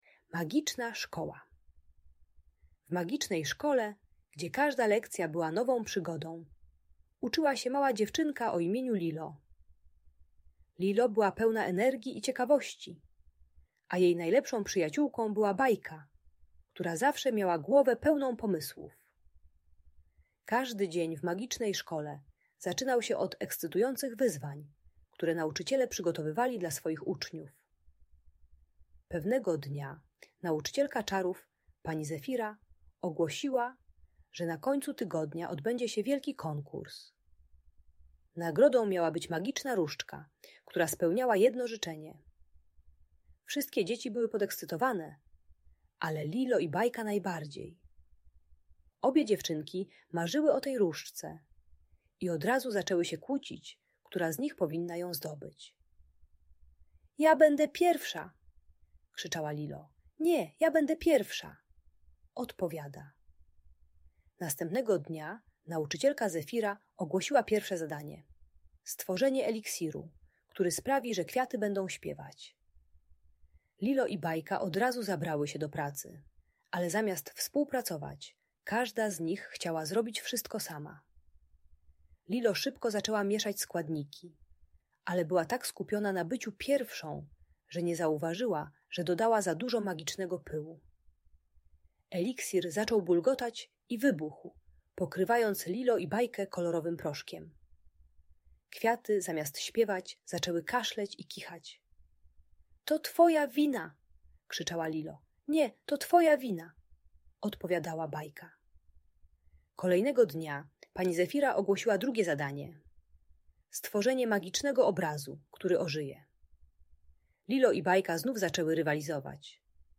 Magiczna Szkoła - Rodzeństwo | Audiobajka